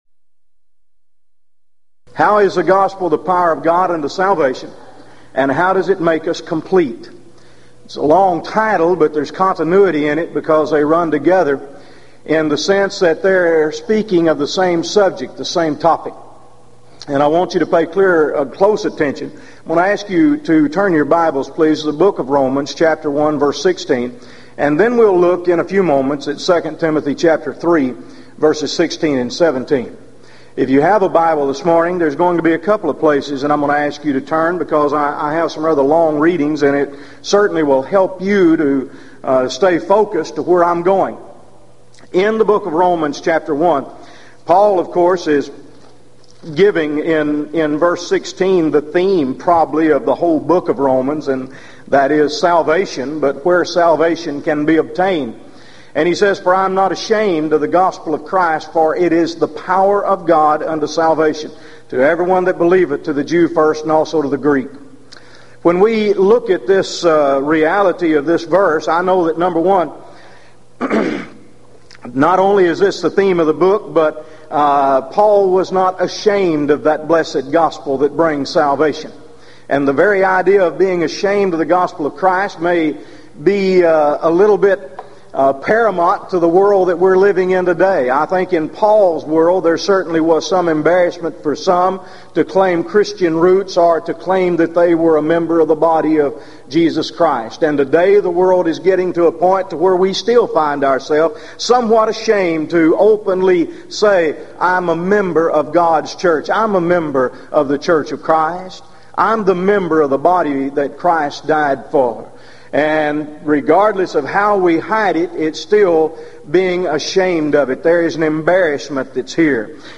Event: 1997 Gulf Coast Lectures
lecture